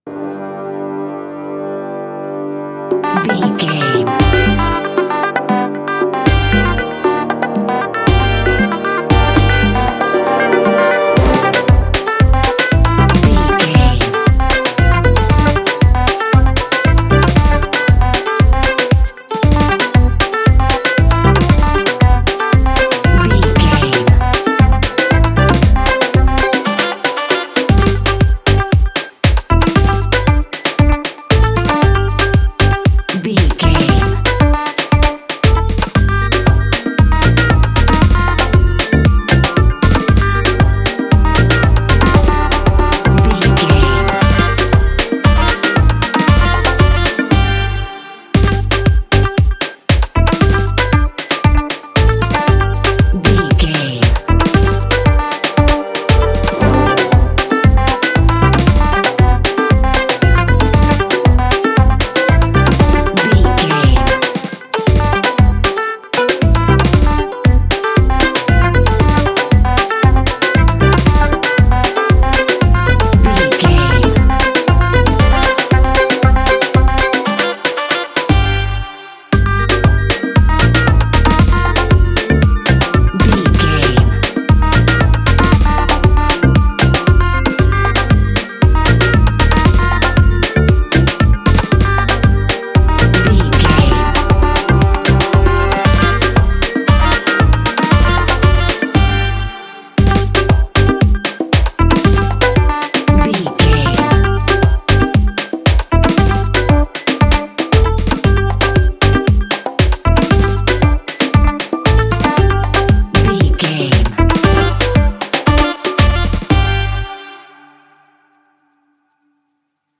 Fast paced
Uplifting
Ionian/Major
catchy
Rhythmic
Pulsating